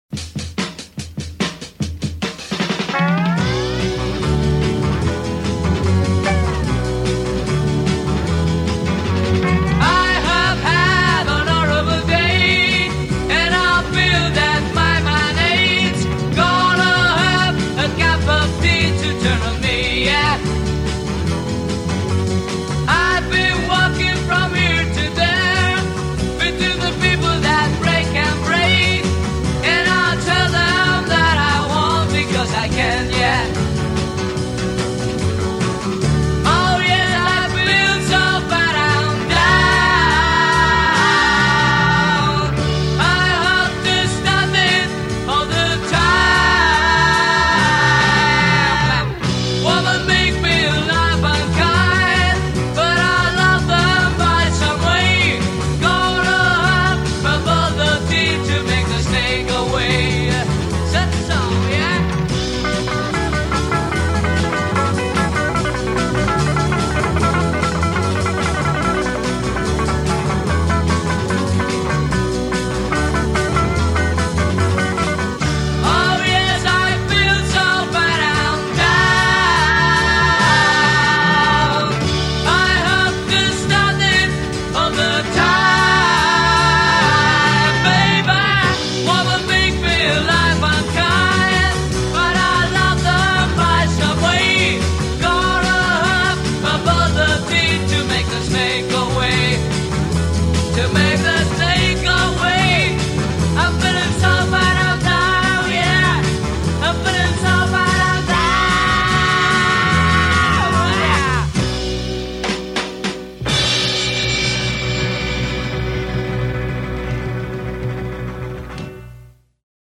Argentinian 60’s garage/beat combo